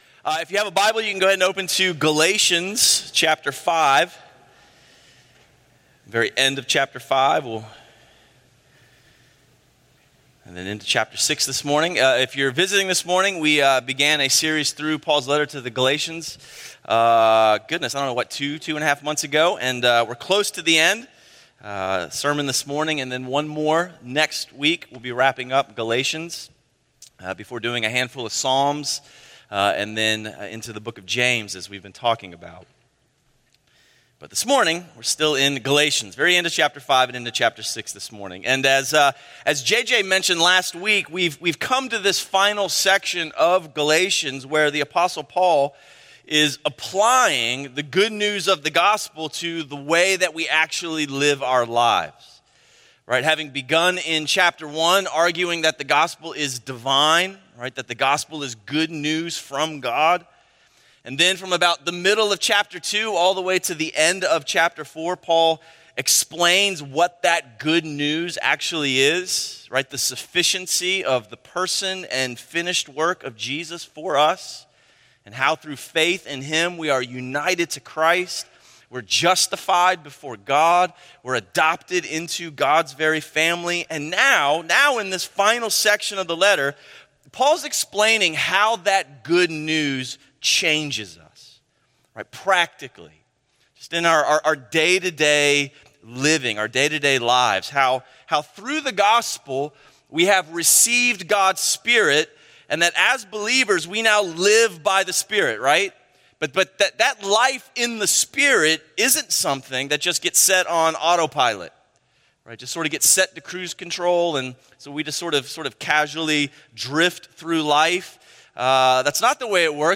A sermon series on Galatians by Crossway Community Church in Charlotte, NC.